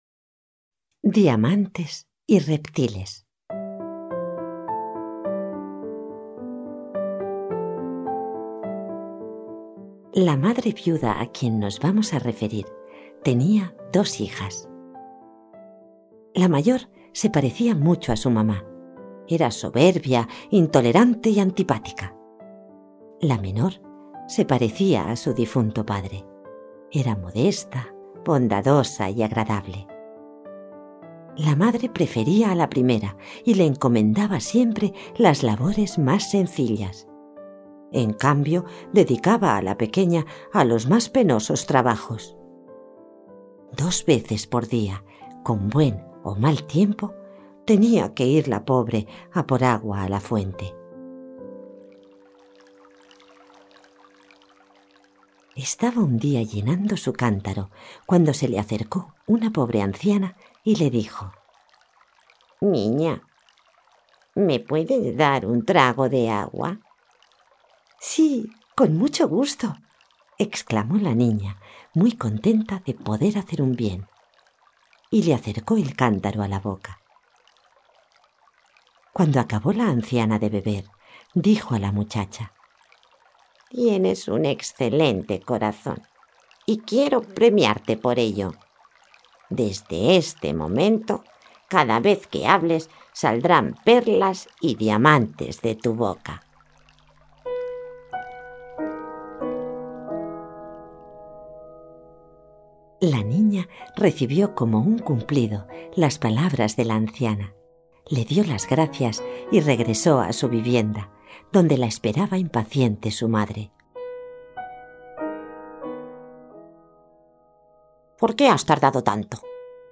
Cuentos clásicos infantiles